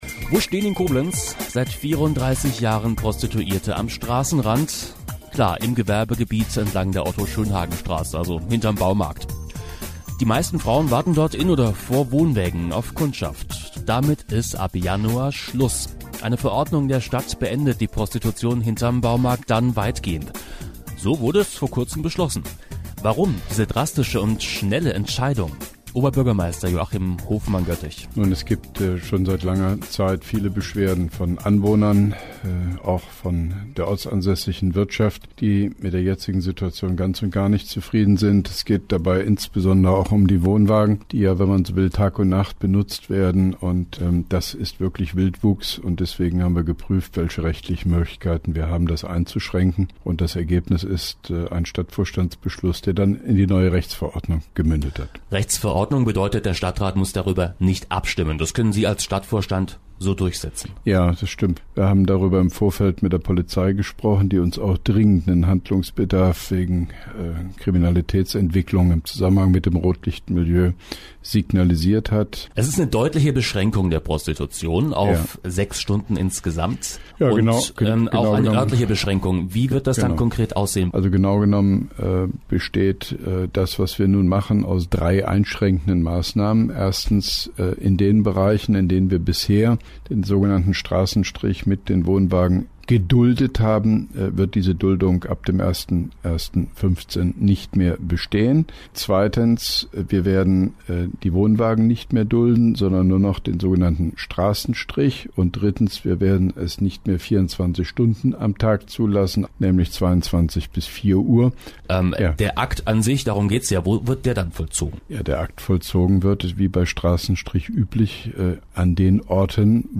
Zur ab 01.01.2015 Koblenzer Rechtsver-ordnung zur Prostitution – Interview mit OB Hofmann-Göttig
Auszug: Antenne Koblenz 98,0 am 29.11.2014 in 2 Teilen, (> Teil 1: Dauer 03:50 Minuten; > Teil 2: Dauer 04:41 Minuten, von 03.56 bis 08.37 Minuten)